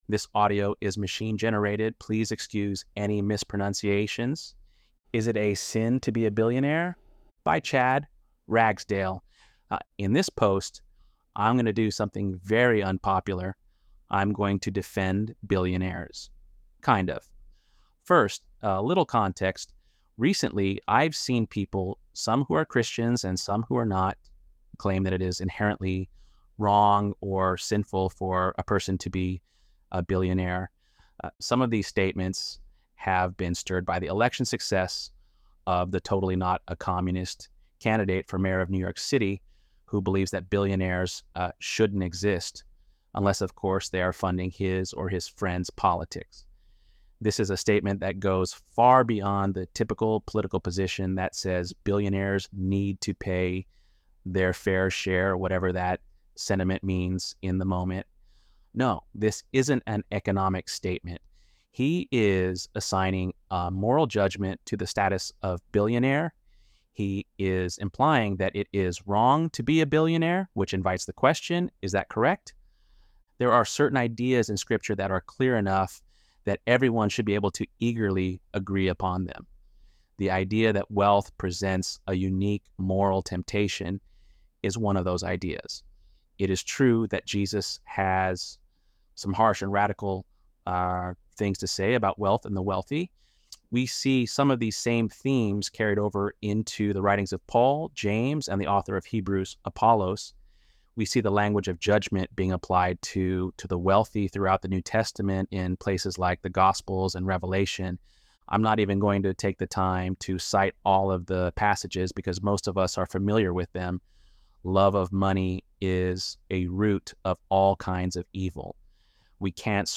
ElevenLabs_8.5_billionare.mp3